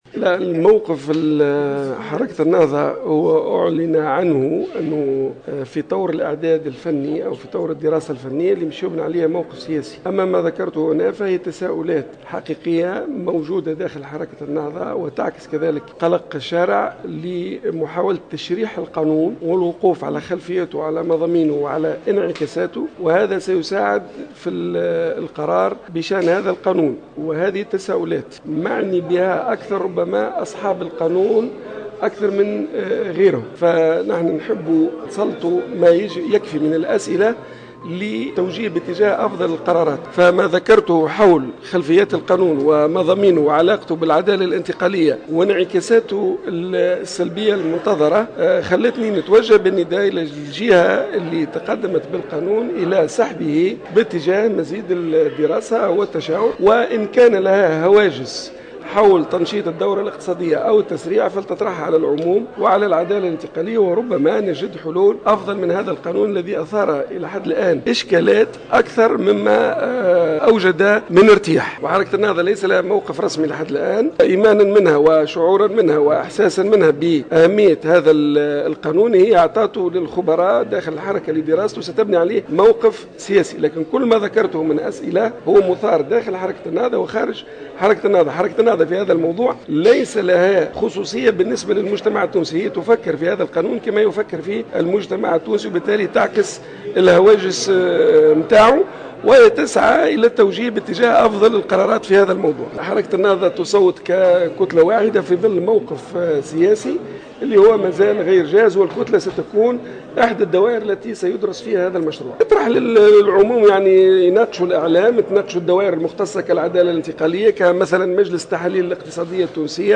وقال المكي في تصريح